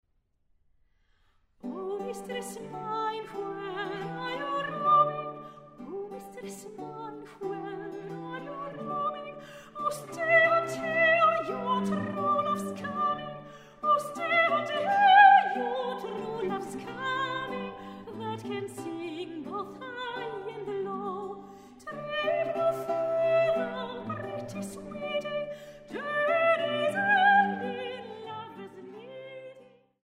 Englische Liebeslieder aus drei Jahrhunderten
Sopran
Blockflöten
Gamben
Laute
Klavier